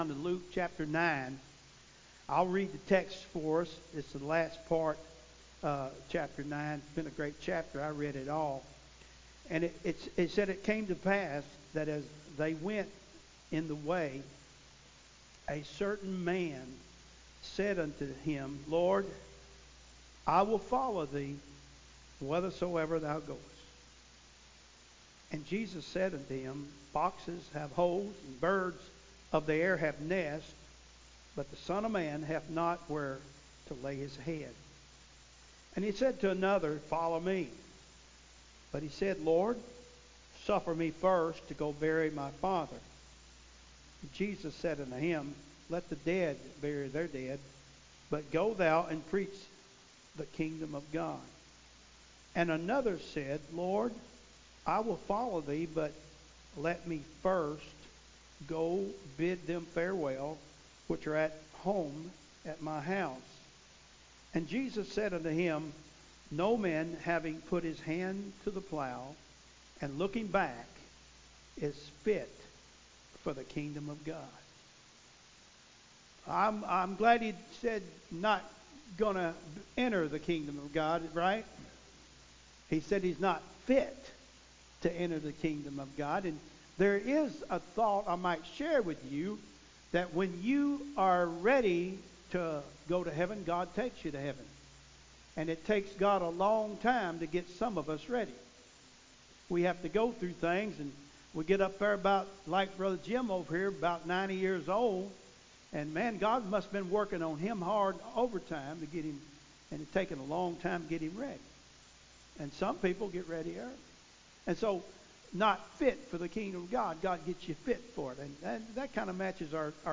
Sermons Sunday Morning Service